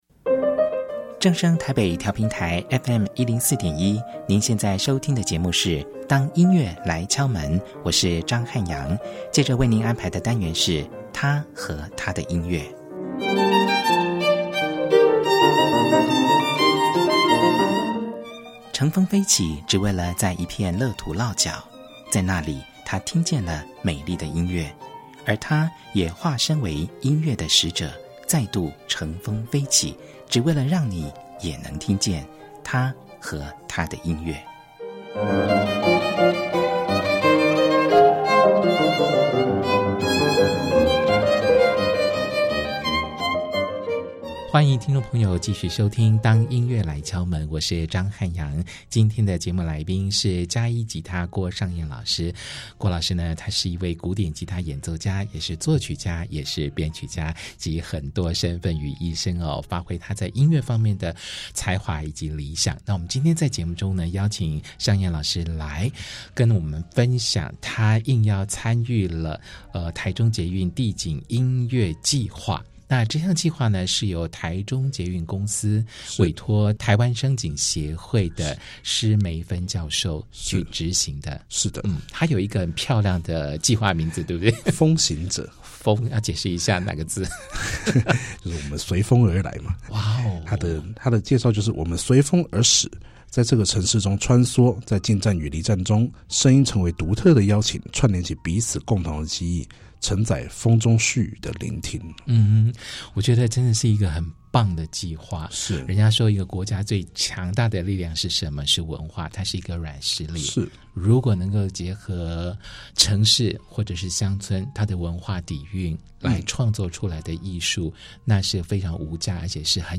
包括兩把古典吉他、小提琴、大提琴、長笛
並選播五首原曲作品及其改編版本